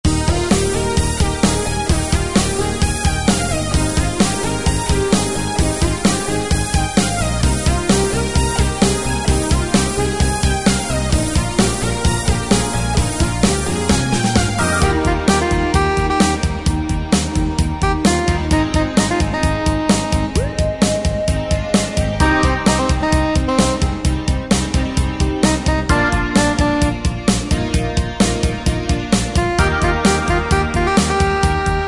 Tempo: 130 BPM.
MP3 with melody DEMO 30s (0.5 MB)zdarma